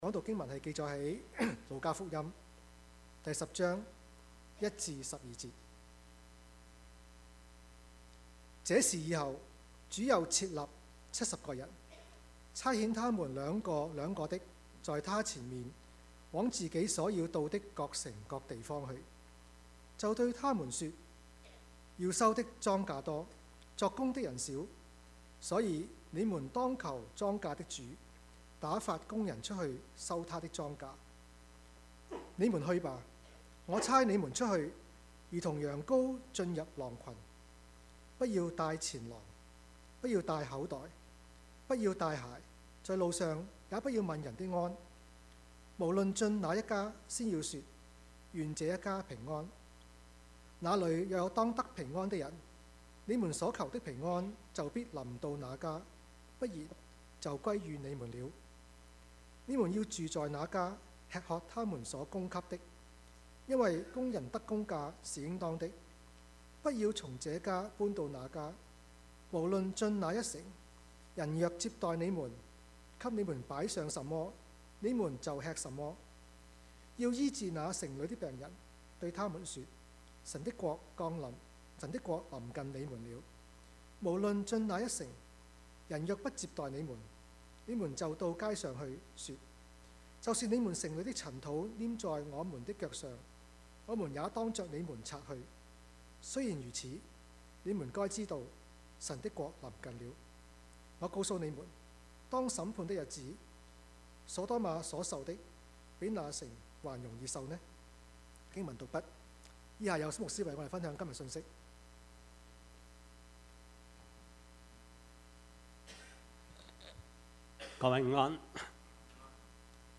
Service Type: 主日崇拜
Topics: 主日證道 « 信與接待 豈不知你們是至幸福的嗎？